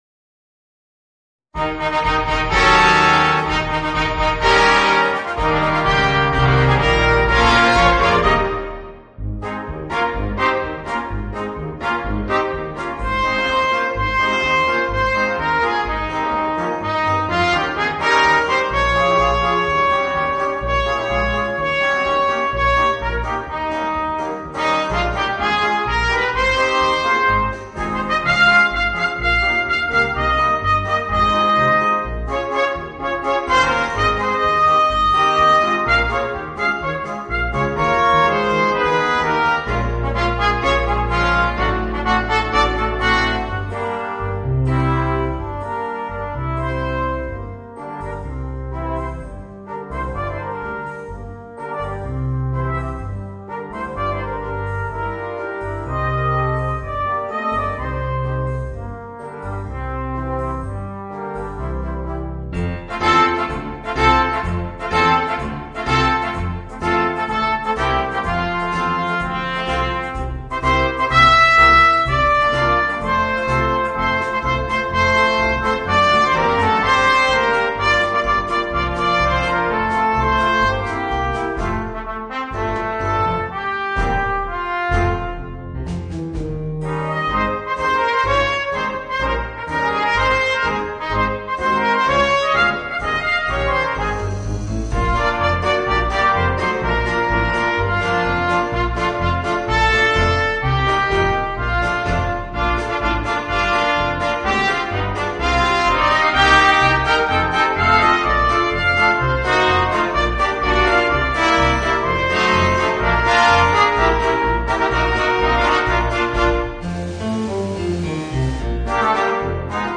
Voicing: 2 Trumpets, Horn, Trombone and Tuba